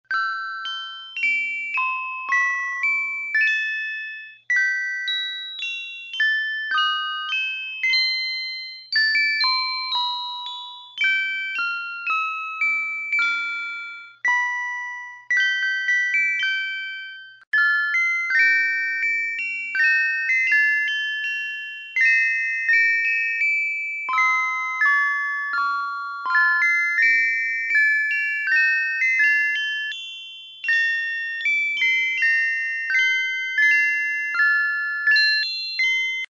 音痴版 (2.5秒)